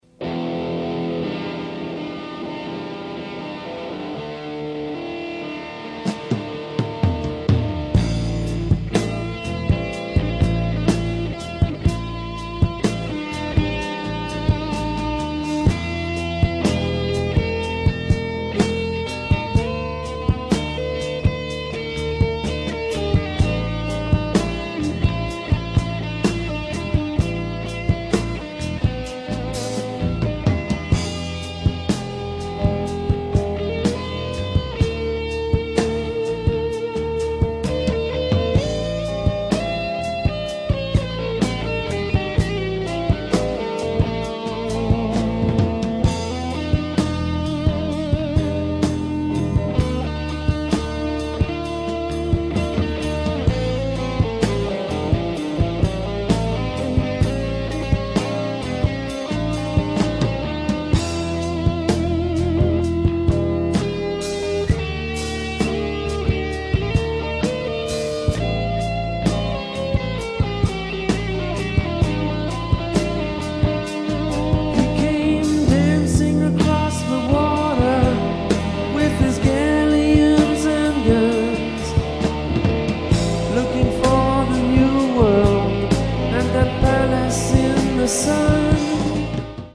Bonne sono mais éclairage minimaliste…